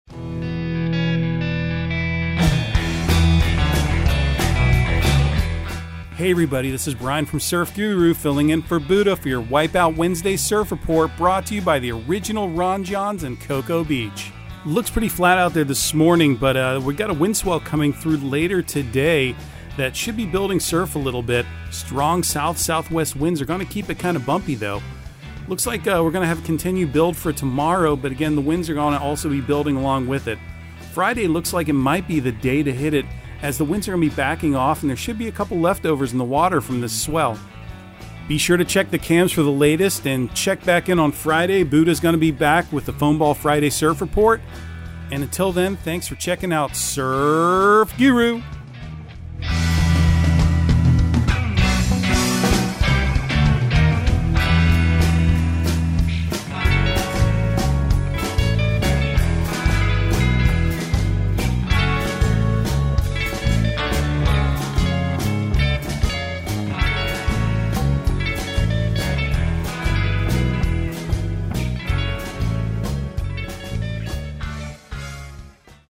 Surf Guru Surf Report and Forecast 03/30/2022 Audio surf report and surf forecast on March 30 for Central Florida and the Southeast.